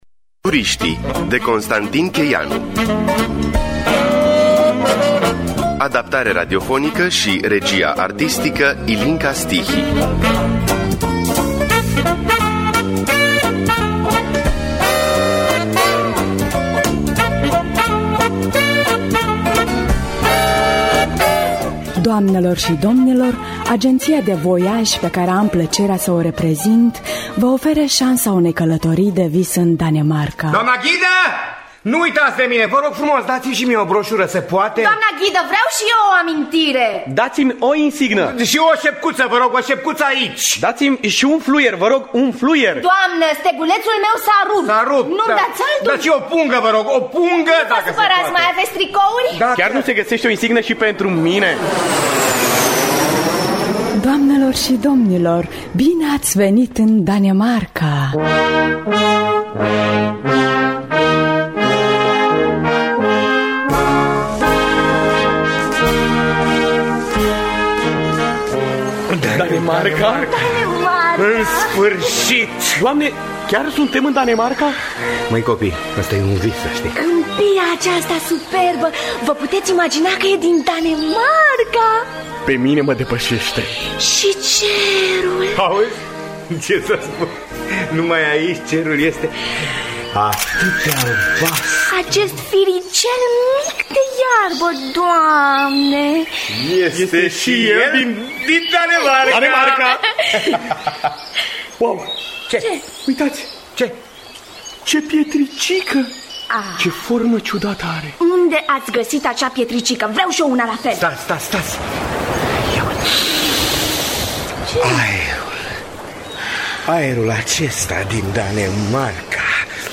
Turiștii de Constantin Cheianu – Teatru Radiofonic Online